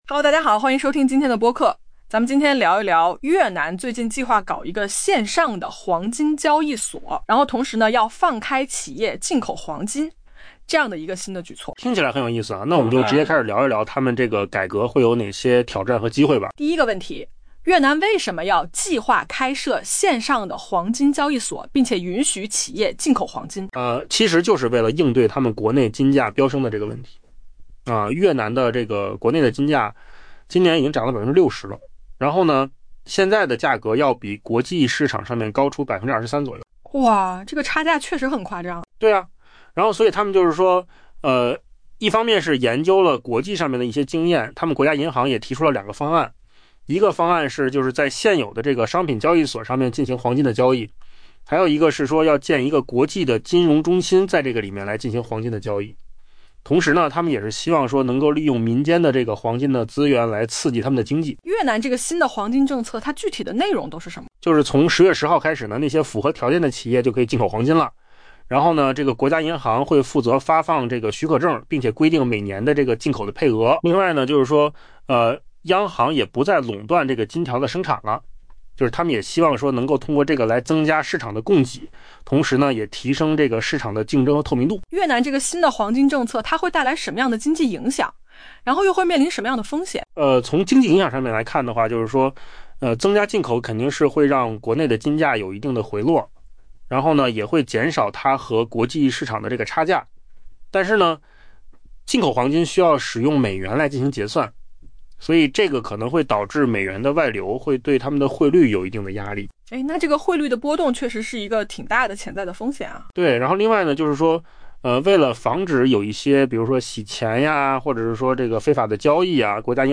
AI 播客：换个方式听新闻 下载 mp3 音频由扣子空间生成 越南计划开设线上黄金交易所，并从下月起允许企业进口黄金，为十年来首次。